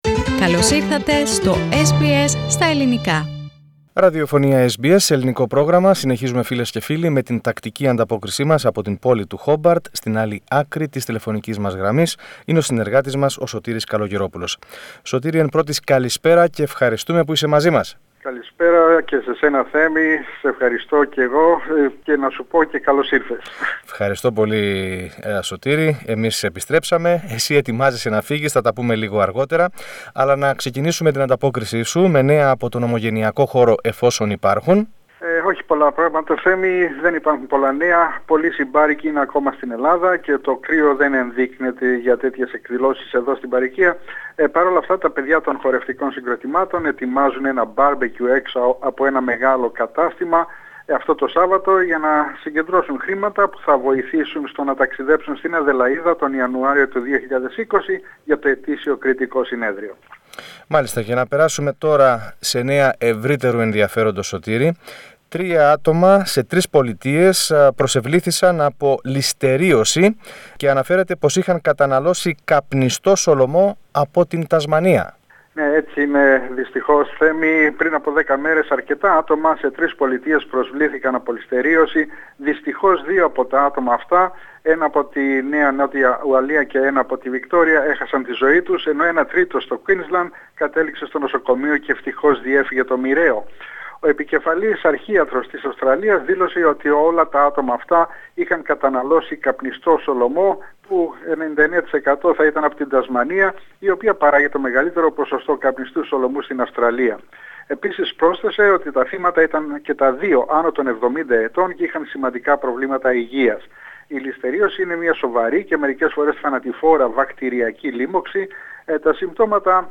More in the weekly report from Hobart with our stringer